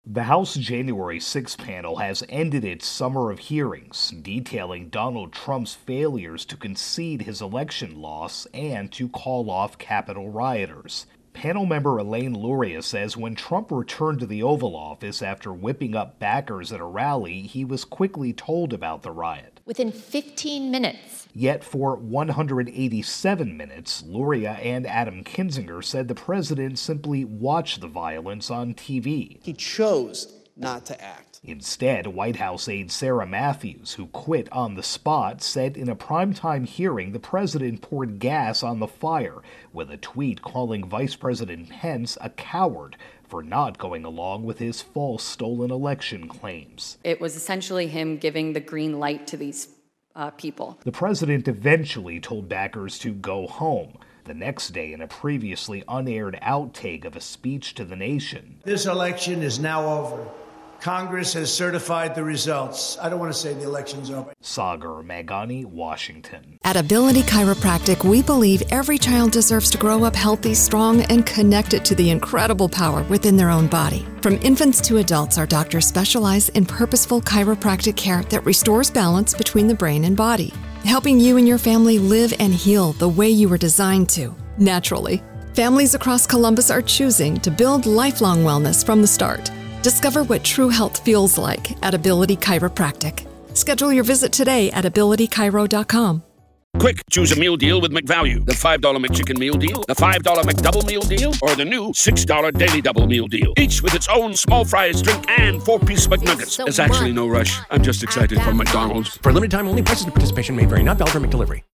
reports on Capitol Riot-Investigation.